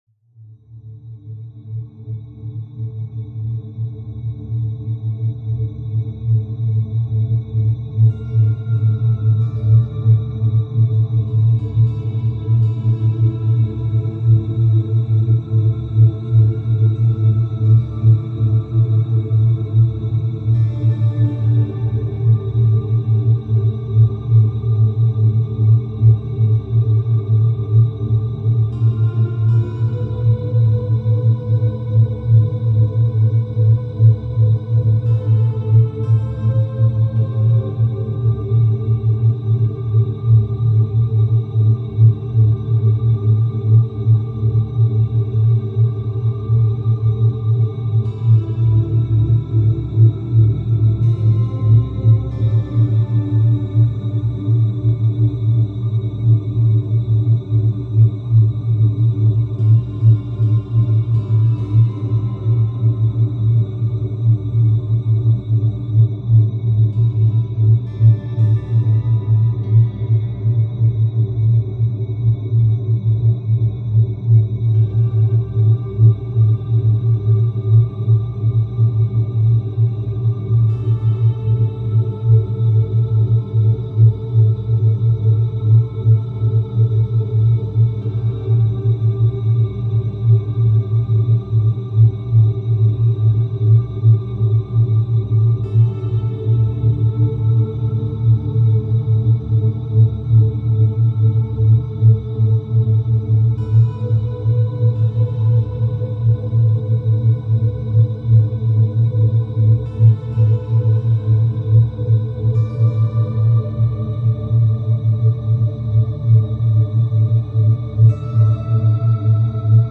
Anti-Aging Sound Therapy – Youth Regeneration Frequencies
Background Sounds, Programming Soundscapes